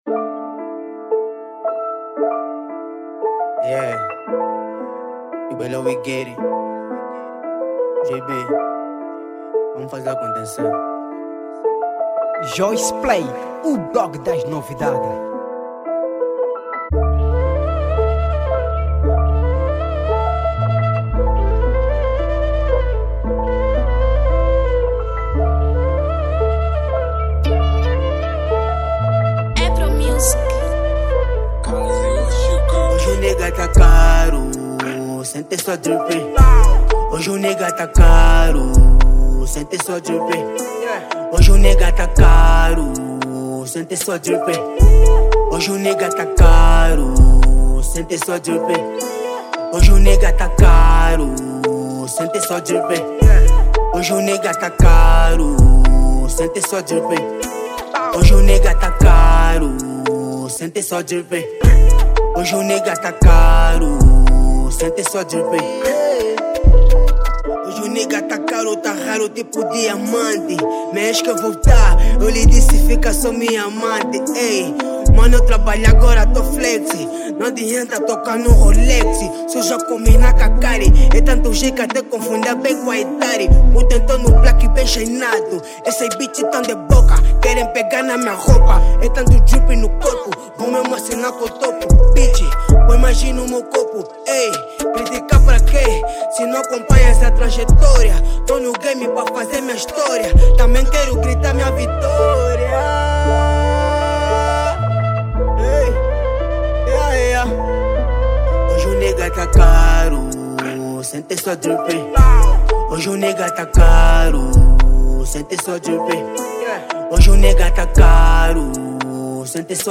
estilo Trap
Género: Hip-Hop / Trap